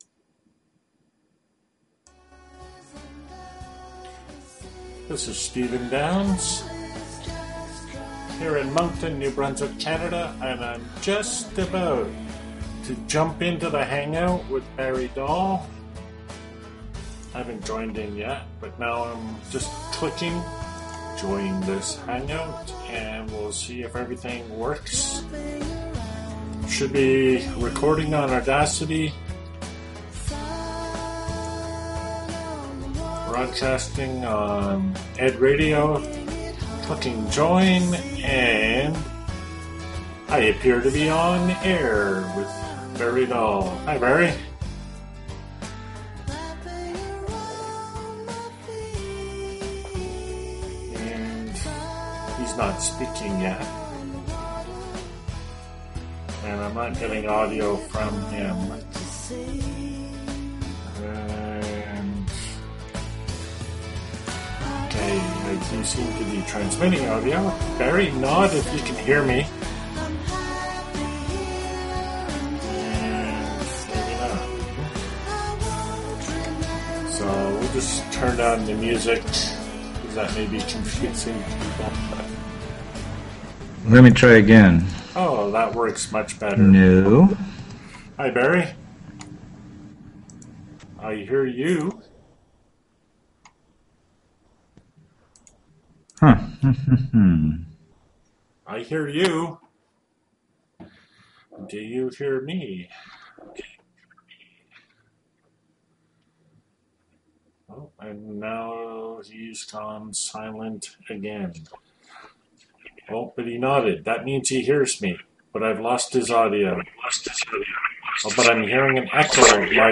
D2L Fusion, Desire2Learn, San Diego via Hangout, Interview, Jul 20, 2012.